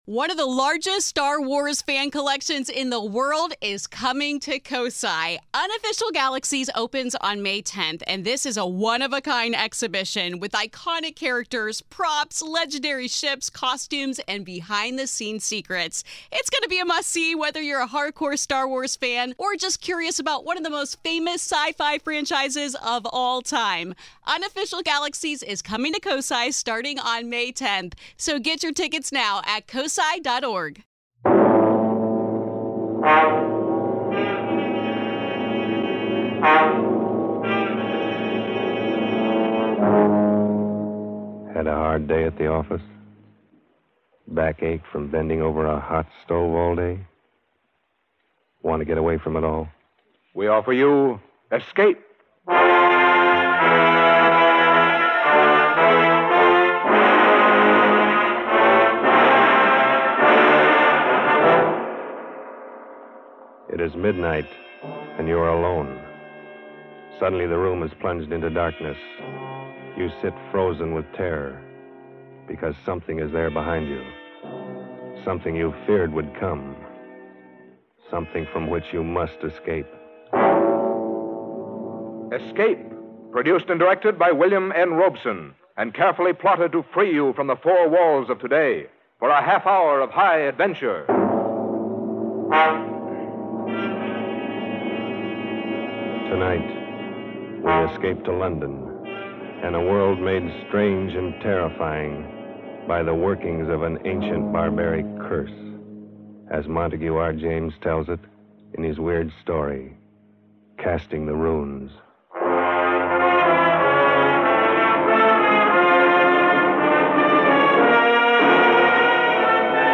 On this week's episode of the Old Time Radiocast, we present you with two stories from the classic radio program Escape.